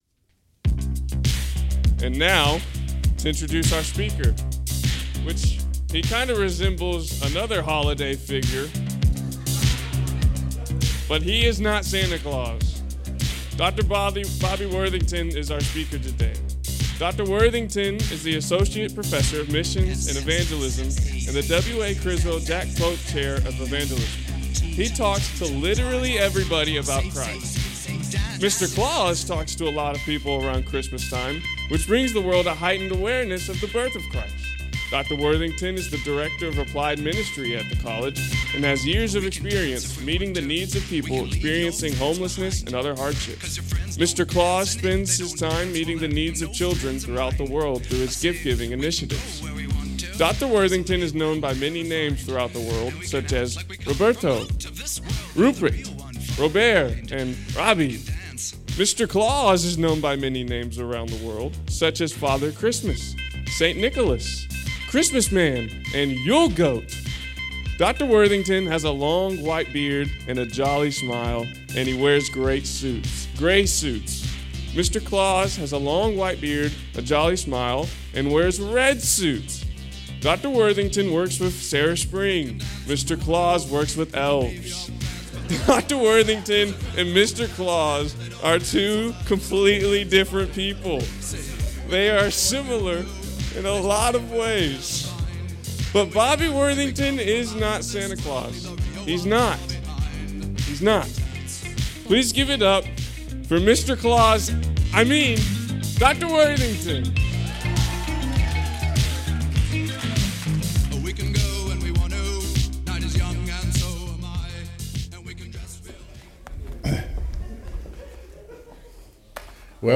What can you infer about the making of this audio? Wednesdays Chapel.